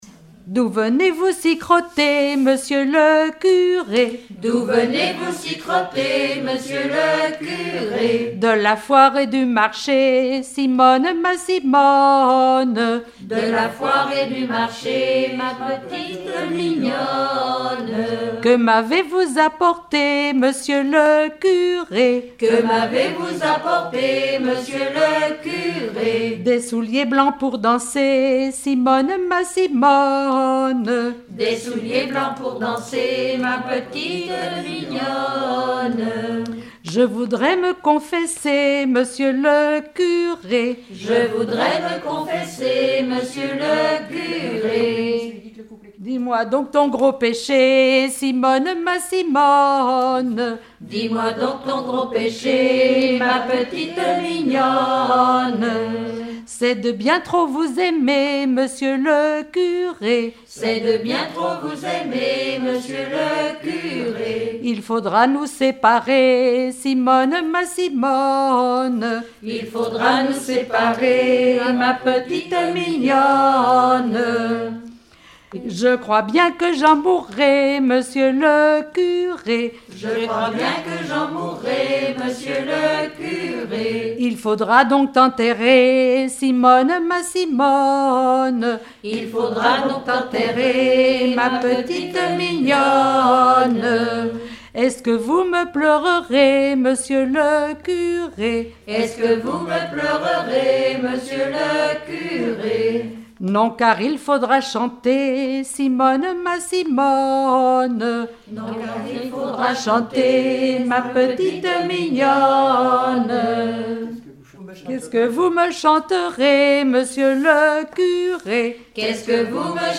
Genre dialogue
Rassemblement de chanteurs
Pièce musicale inédite